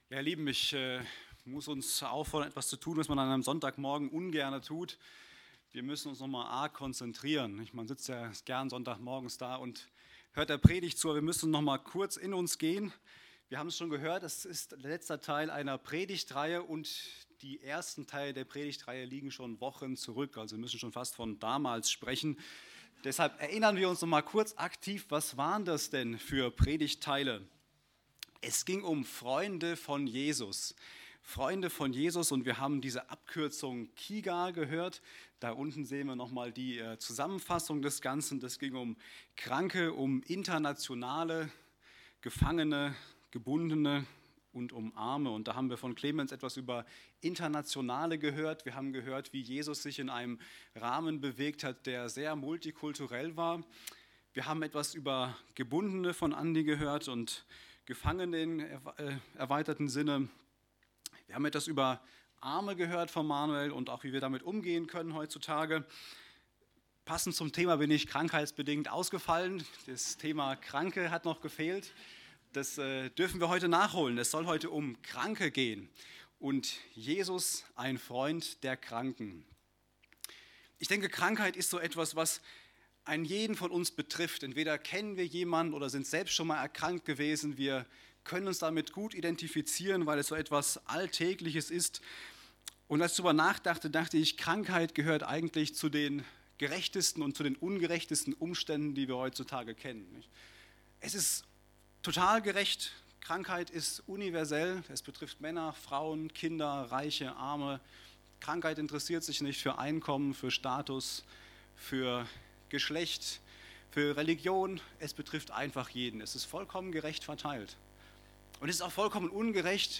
Aus der Predigtreihe: "Freunde von Jesus"